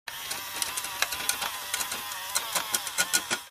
fo_toy_motor_08_hpx
Small toy motor spins at variable speeds. Motor, Toy Buzz, Motor